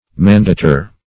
Mandator \Man*da"tor\, n. [L.]